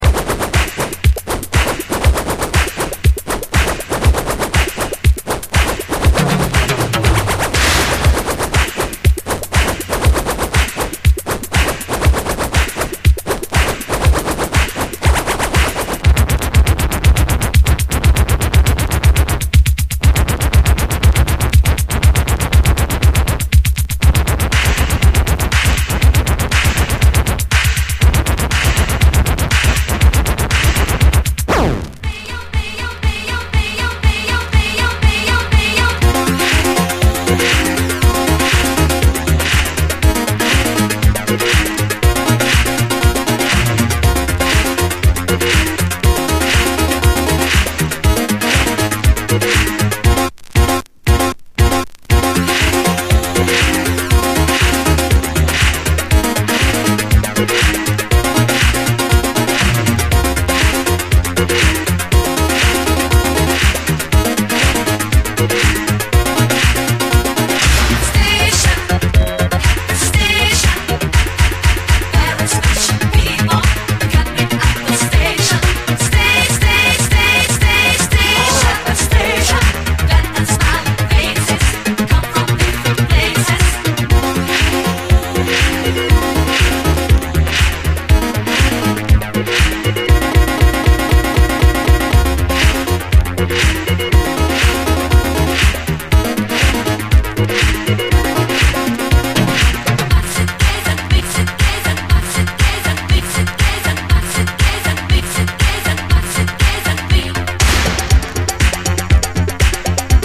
DISCO, HIPHOP
ギャル２人組のイタロ〜シンセ・ディスコ・グループのデビュー曲にして、